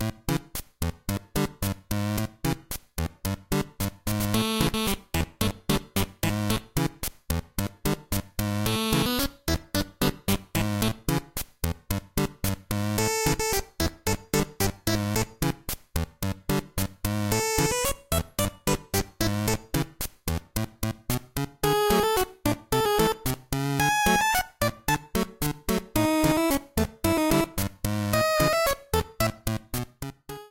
A music track